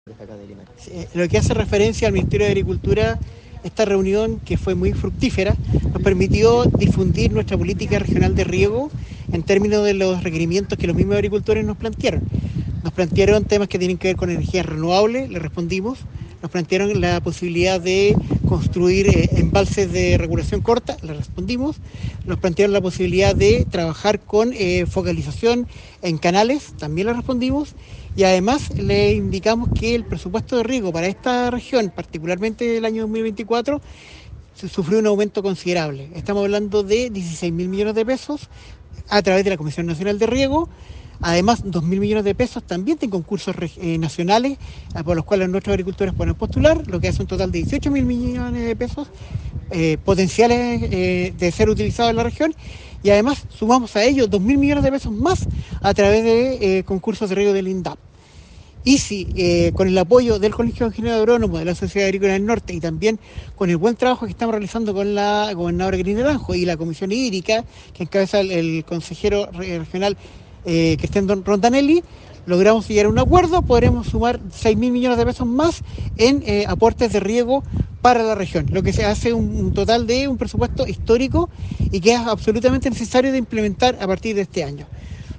En cuanto al apoyo del Estado en materia de fomento a la agricultura y especialmente en términos de uso y tecnificación del agua, el Seremi de Agricultura Cristian Álvarez puntualizó que